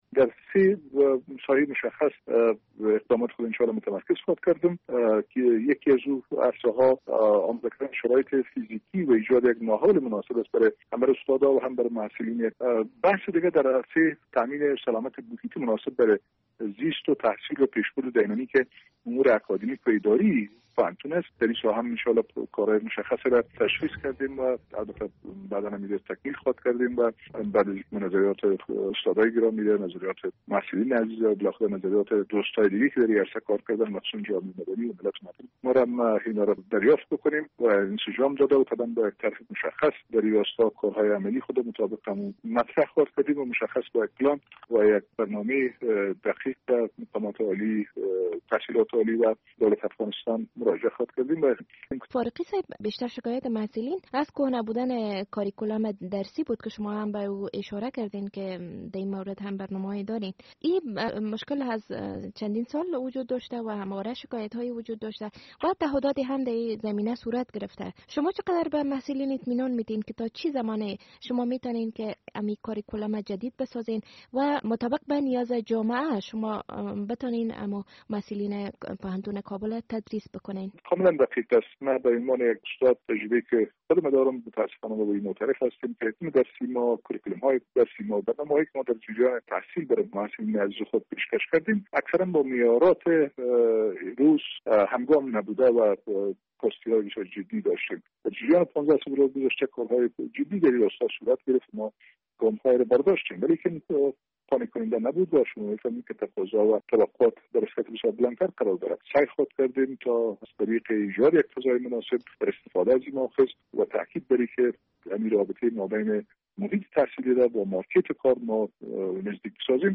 مصاحبه با حمیدالله فاروقی رئیس جدید پوهنتون کابل